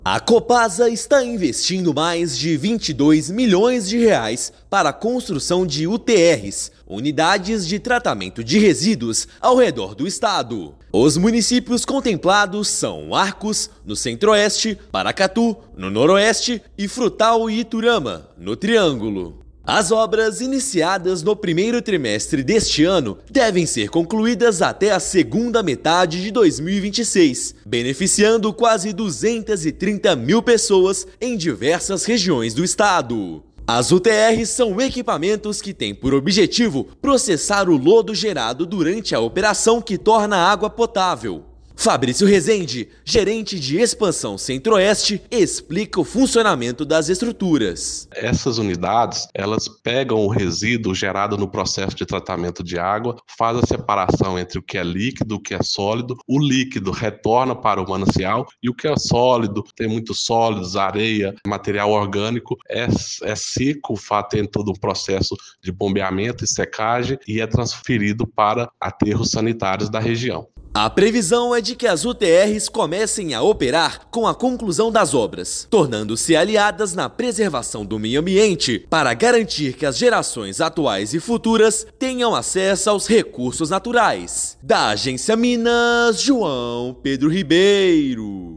Aporte contribuirá para preservação do meio ambiente nas cidades de Arcos, Frutal, Iturama e Paracatu. Ouça matéria de rádio.